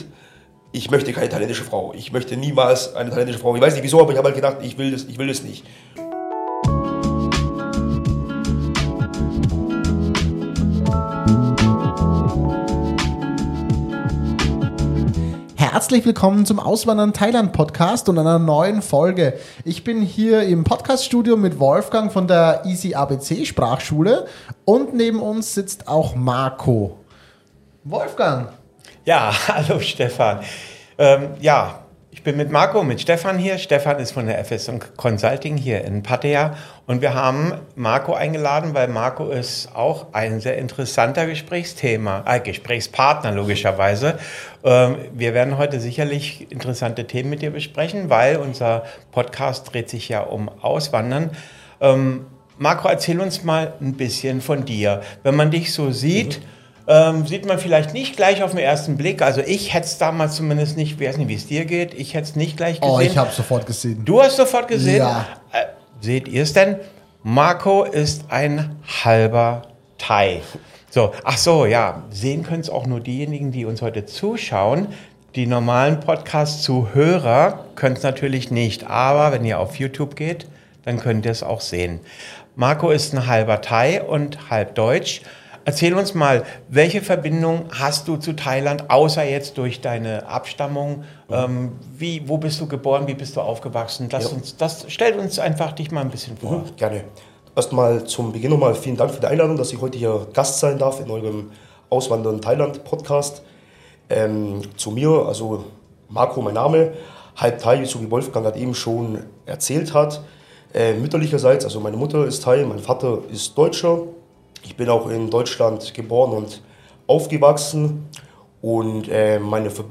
#18 – Interview: Auswandern mit Plan
18-interview-auswandern-mit-plan.mp3